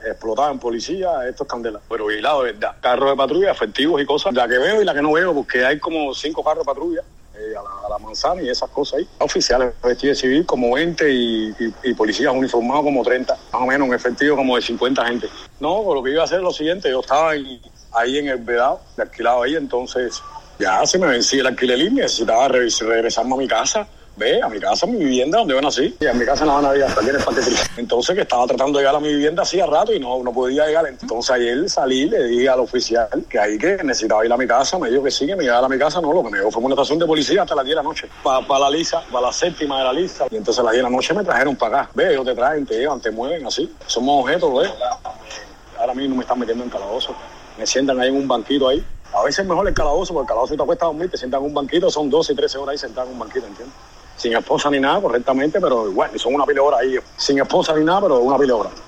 Declaraciones de Maykel Castillo "El Osorbo" a Radio Martí